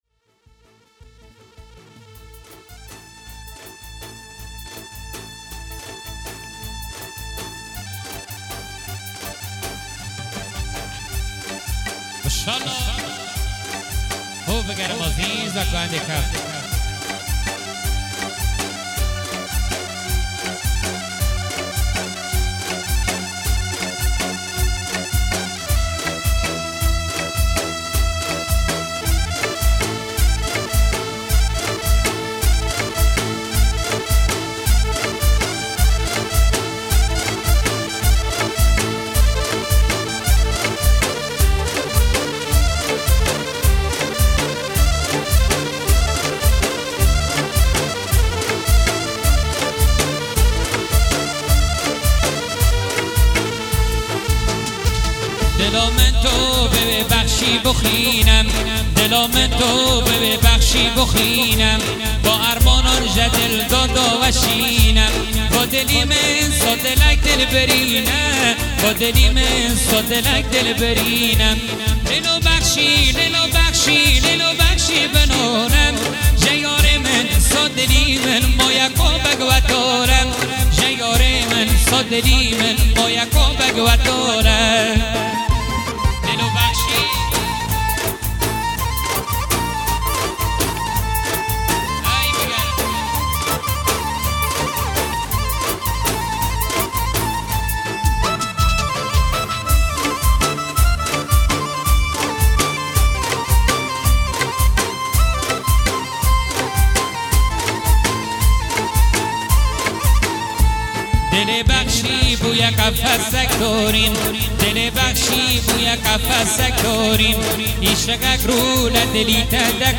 آهنگ ارکستری جدید با لینک مستقیم
دانلود آهنگ کرمانجی